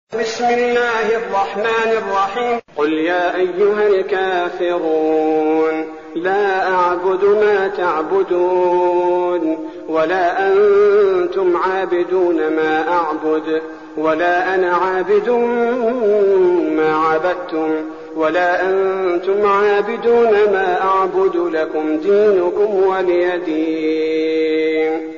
المكان: المسجد النبوي الشيخ: فضيلة الشيخ عبدالباري الثبيتي فضيلة الشيخ عبدالباري الثبيتي الكافرون